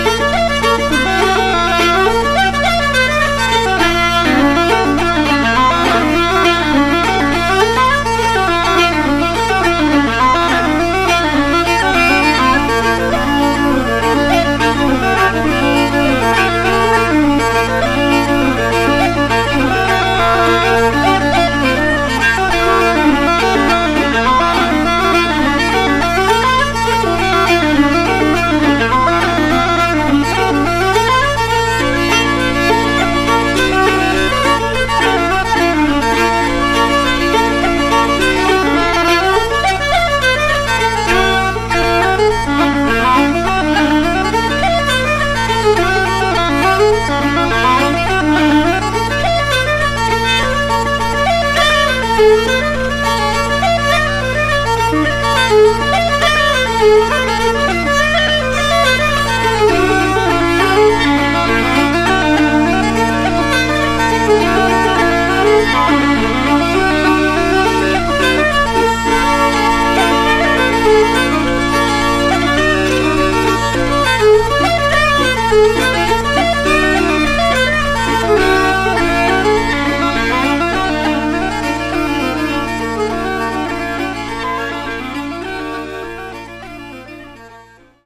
fiddle, hardanger fiddle, whistle